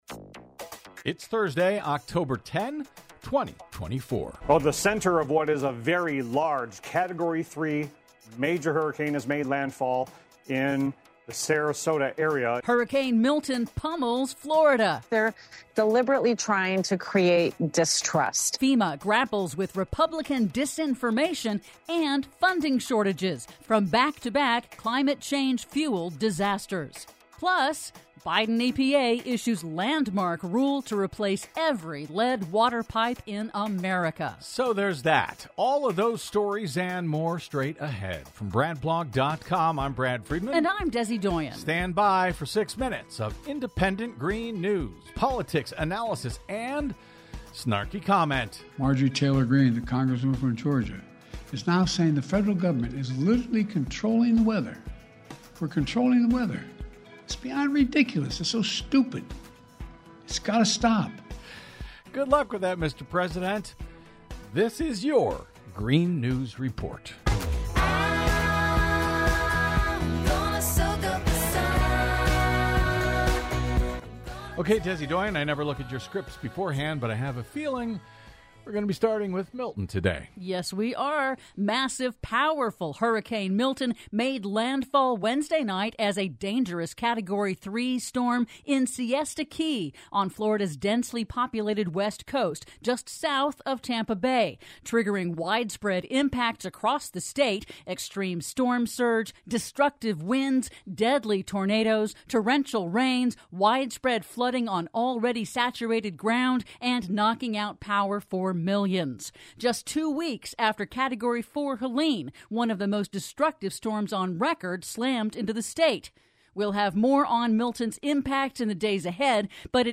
GNR's now celebrating 16 YEARS of independent green news, politics, analysis, snarky comment and connecting climate change dots over your public airwaves!